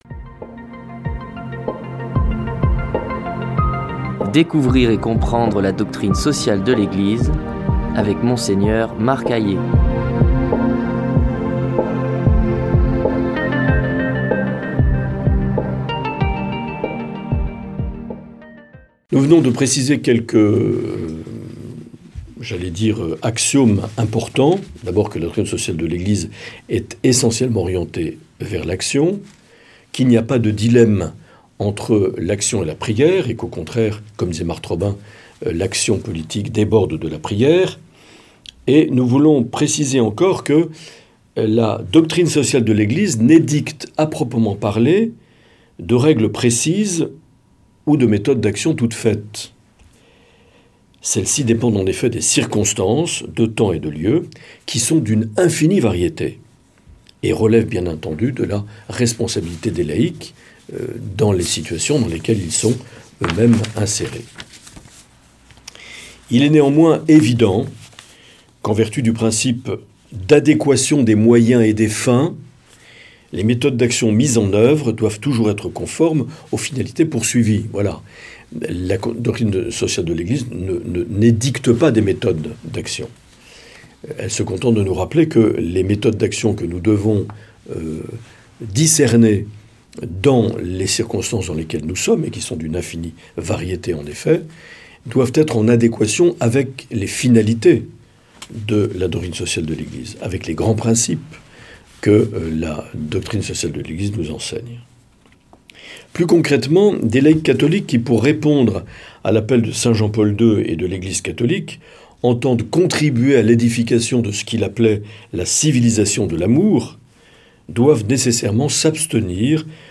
Une émission présentée par
Présentateur(trice)